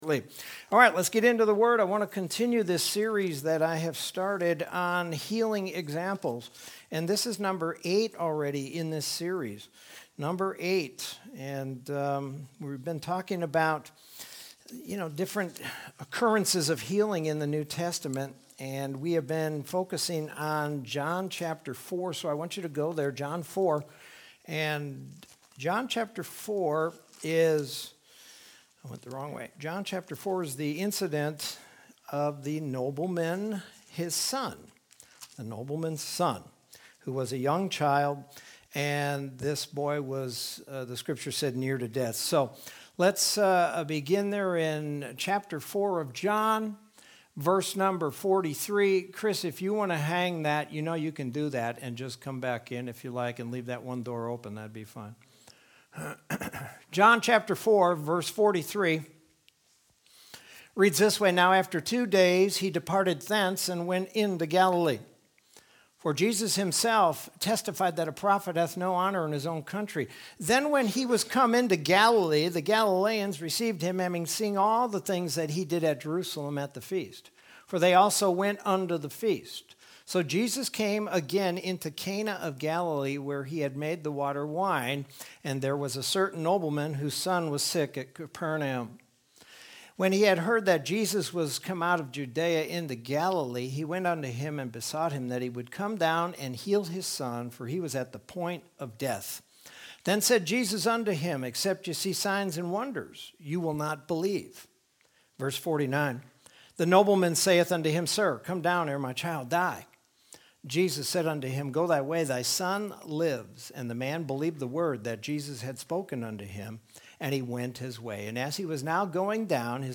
Sermon from Wednesday, March 10th, 2021.